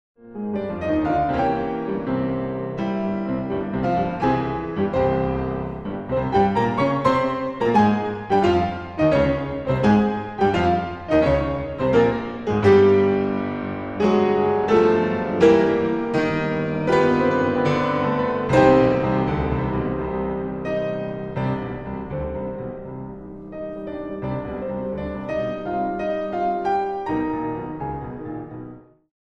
Arrangement for 2 pianos, 8 hands